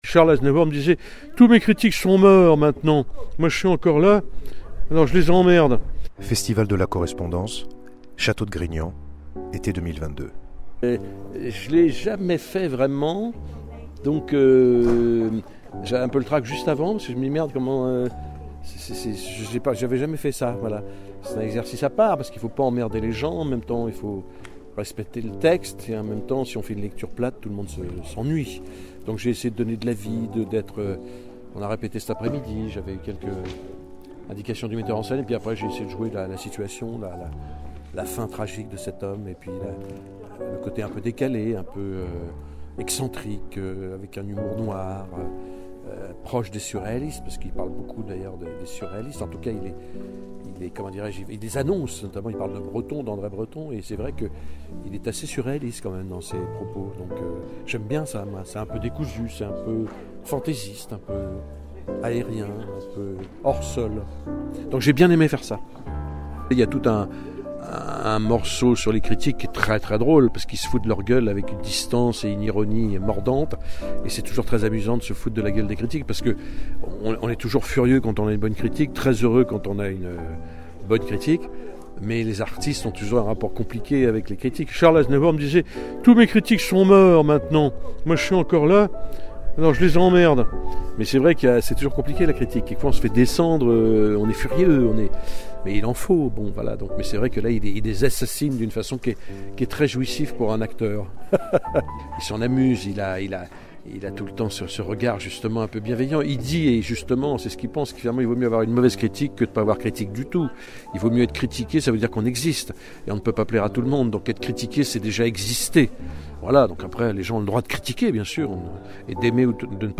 Antoine Duléry dans la peau d'Éric Satie. Interview radio du cinema